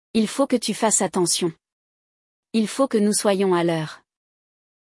No episódio de hoje, vamos acompanhar uma conversa entre dois amigos, em que um deles alerta o outro sobre um hábito questionável: “fouiller” as mensagens do namorado.